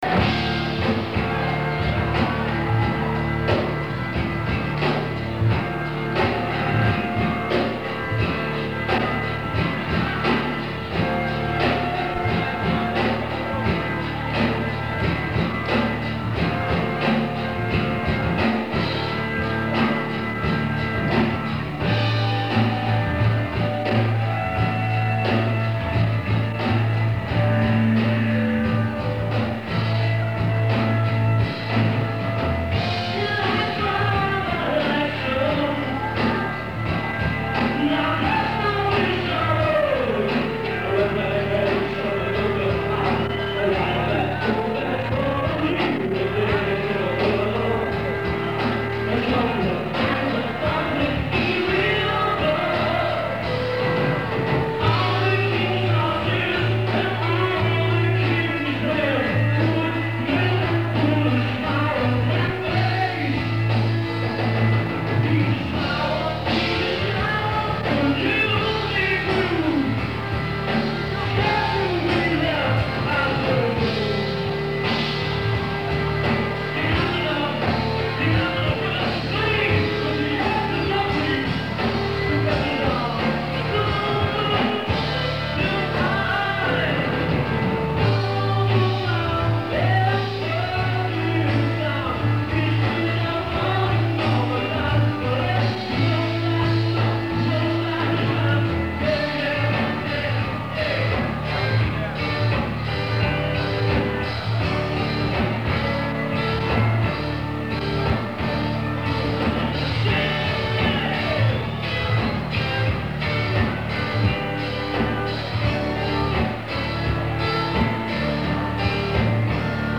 Guitar
Bass
Drums